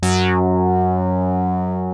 OSCAR 8 F#3.wav